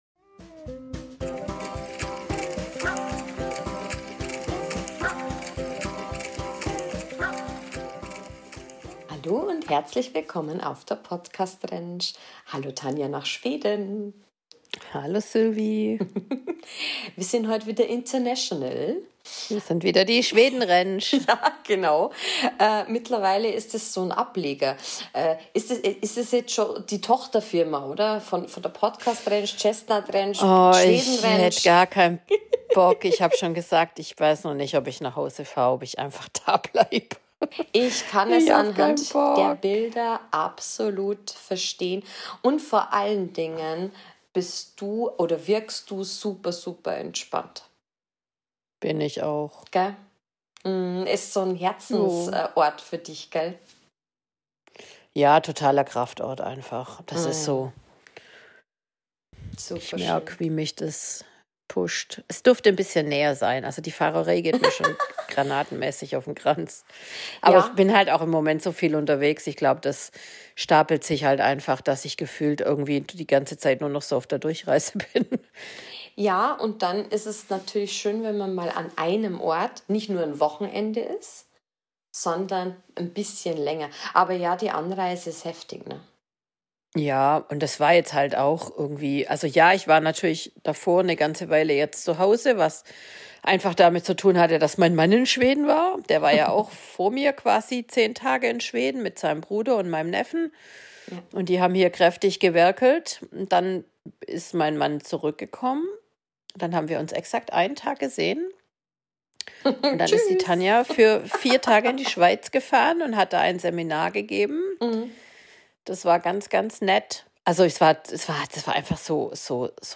Heute wieder live aus Schweden!